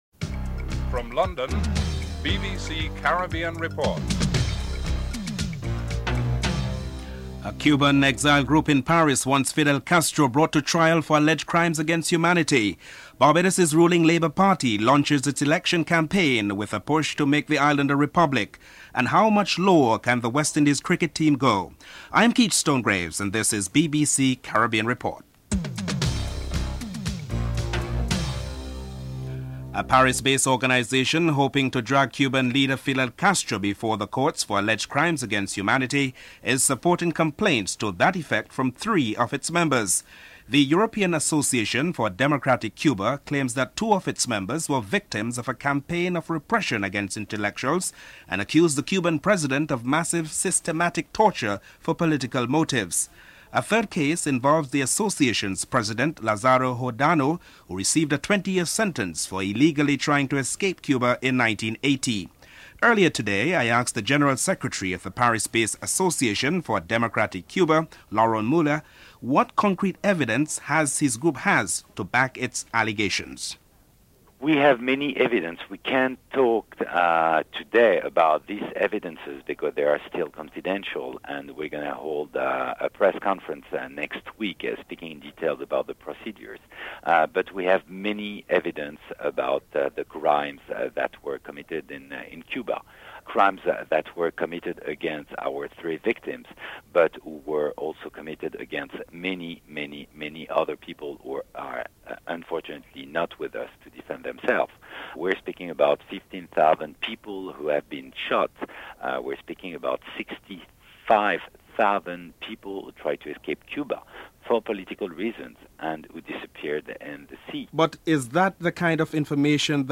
6. The West Indies have lost their fourth consecutive test match in South Africa. Both West Indies cricket team manager Clive Lloyd, and former West Indies fast bowler, Colin Croft are interviewed (12:26-15:19)